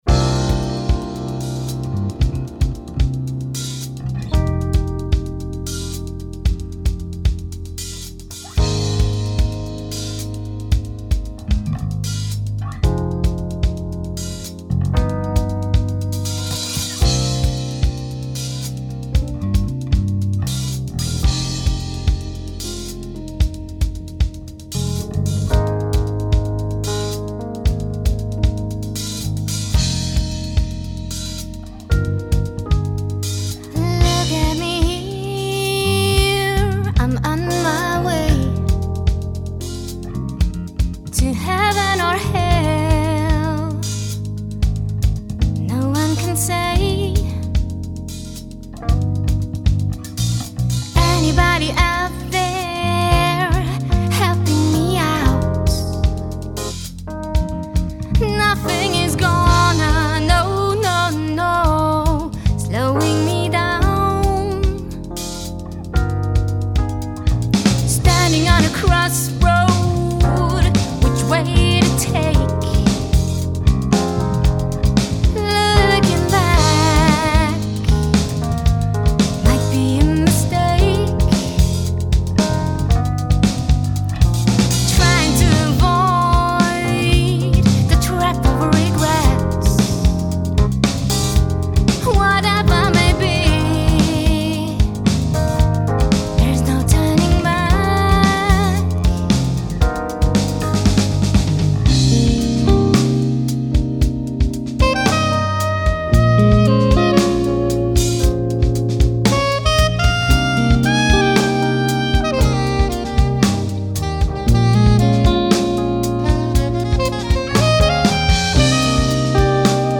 Mit Hilfe meiner aktuellen Band ist eine wundervolle Aufnahme in der Power Play Studios im Juli 21 entstanden (siehe Blog 20).